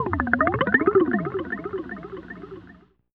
Index of /90_sSampleCDs/Zero-G - Total Drum Bass/Instruments - 2/track39 (Guitars)
03 Fretting About C.wav